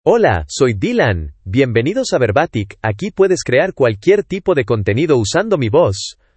MaleSpanish (United States)
Dylan is a male AI voice for Spanish (United States).
Voice sample
Listen to Dylan's male Spanish voice.
Dylan delivers clear pronunciation with authentic United States Spanish intonation, making your content sound professionally produced.